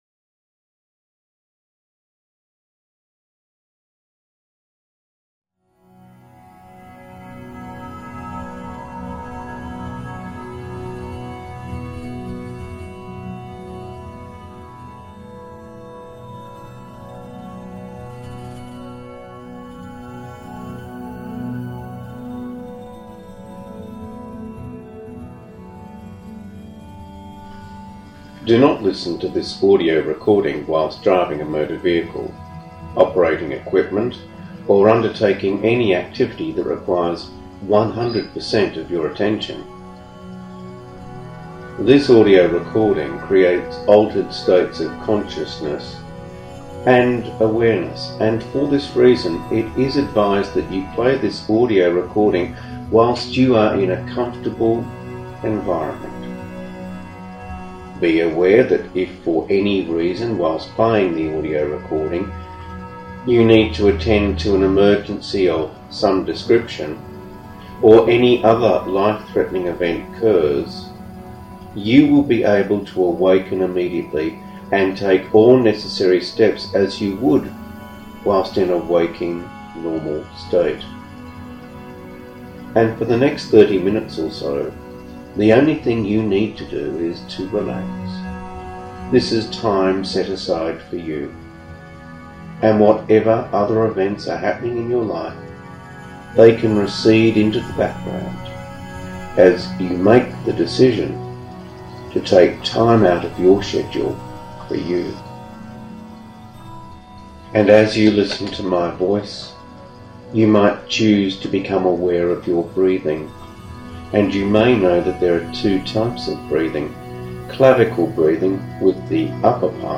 560.03 – Self Imp – Work Life Balance – Hypnosis
560.03-Self-Imp-Work-Life-Balance-Hypnosis.mp3